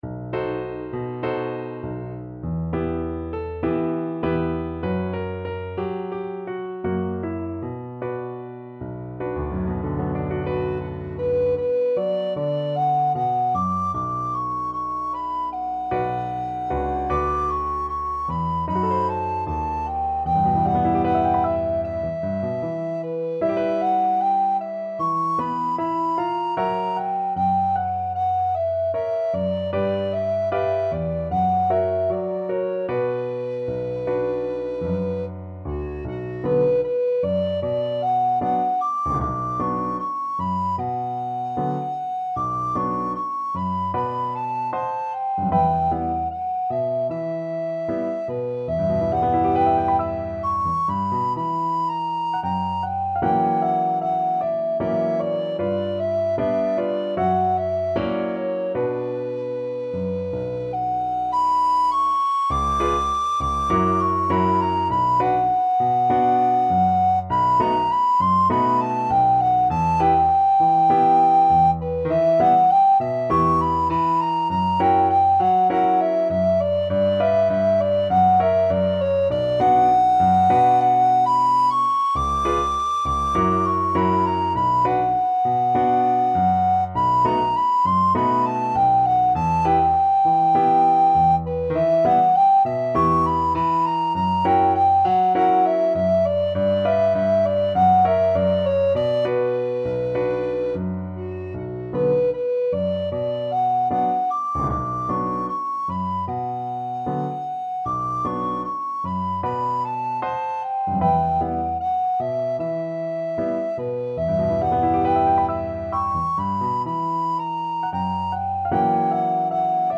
4/4 (View more 4/4 Music)
Moderato =c.100